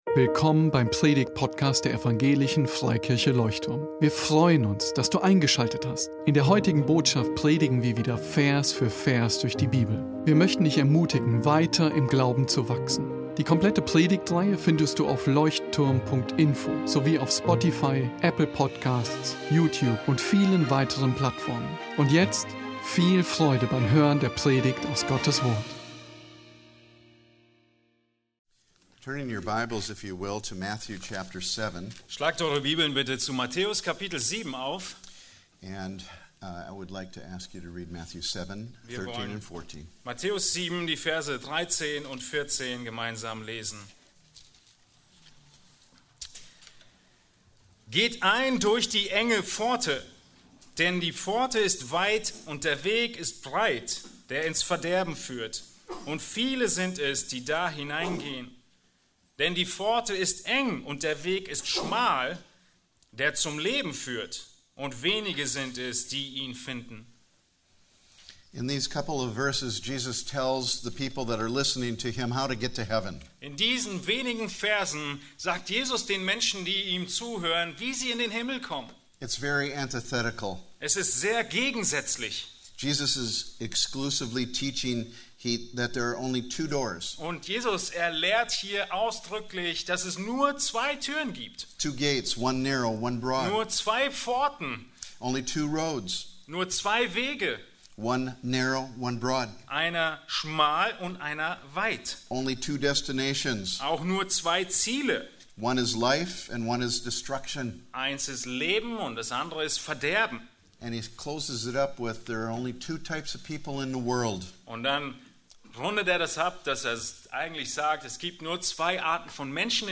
Der enge und der breite Weg – zwei Wege, zwei Ziele ~ Leuchtturm Predigtpodcast Podcast